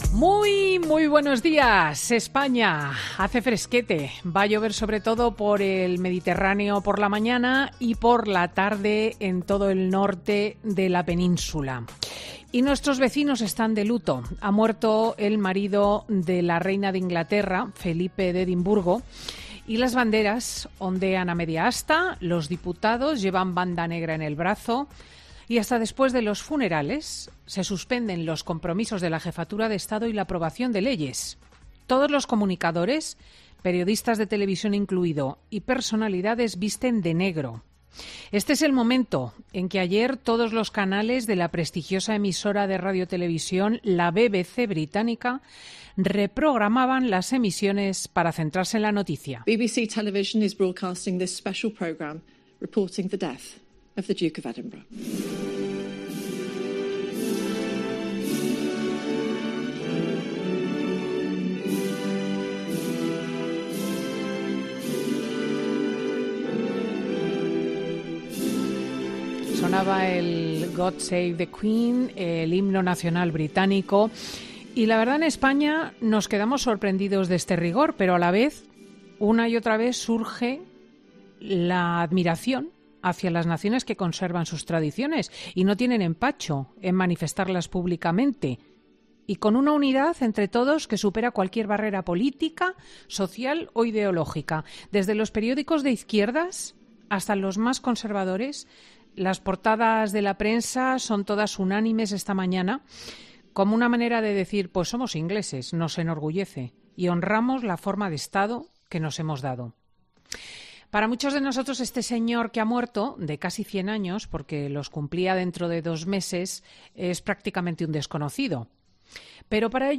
La muerte de Felipe de Edimburgo y la "confusión" y "caos" a cuenta de las vacunas en España, en el monólogo de la comunicadora de 'Fin de Semana'